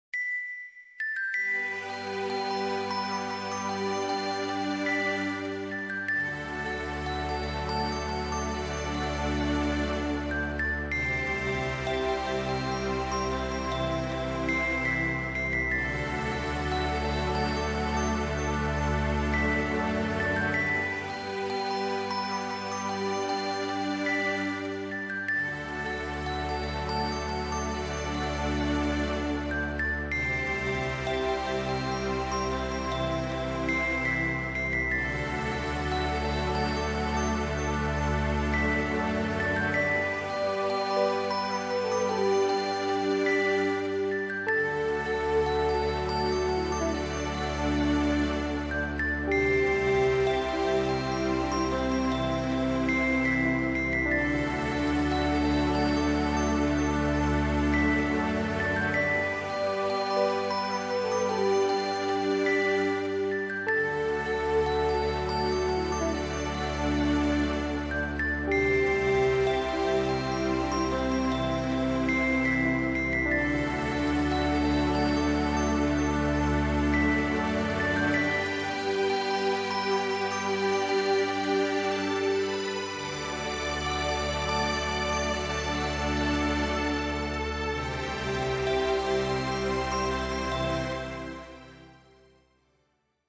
Znakomita muzyka do relaksu czy medytacji.